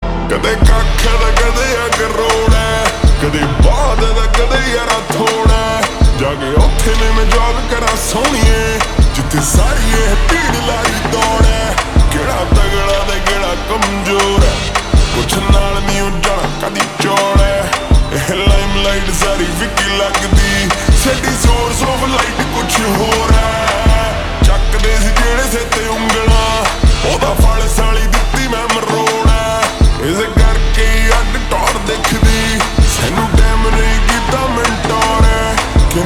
Punjabi Songs
Slow Reverb Version
• Simple and Lofi sound
• Crisp and clear sound